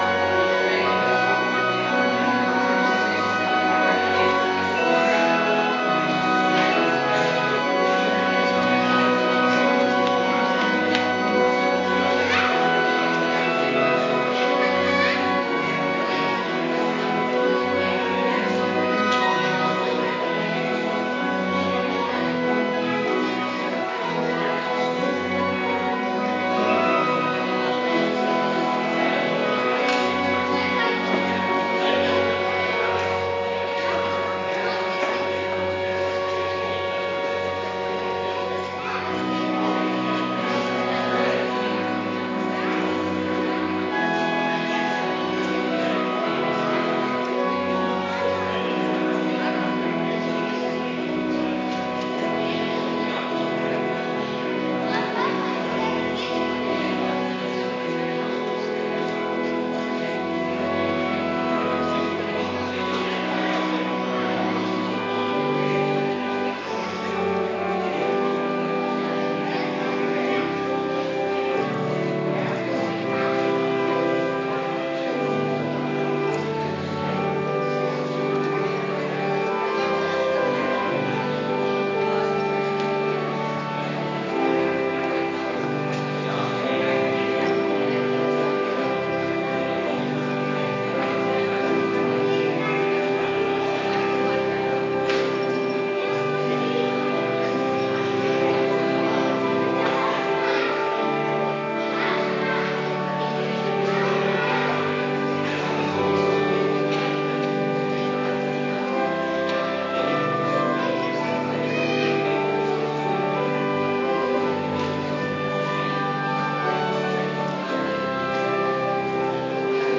Adventkerk Zondag week 39